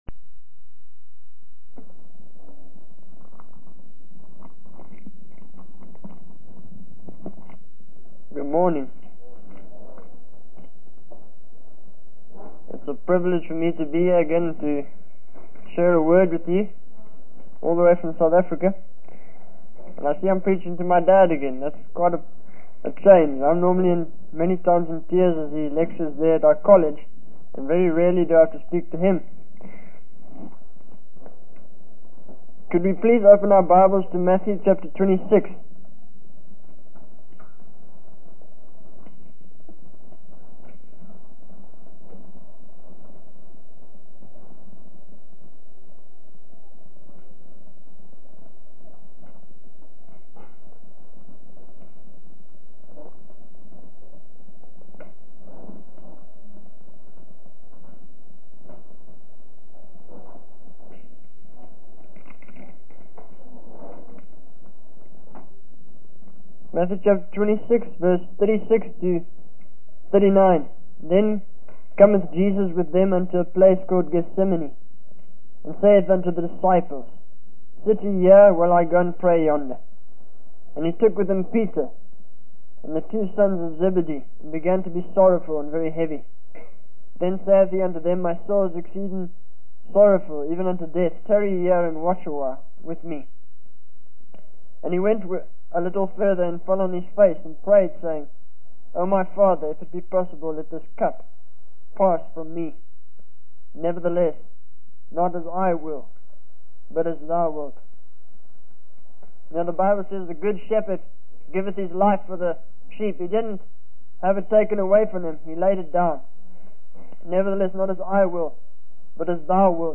In this sermon, the speaker emphasizes the importance of not turning down the call of God for personal gain or ambition. He references Matthew 9:36-38, where Jesus sees the multitude and is moved with compassion because they are like sheep without a shepherd. The speaker urges the audience to pray for laborers to be sent into the harvest, as God desires to gather and save those who are scattered.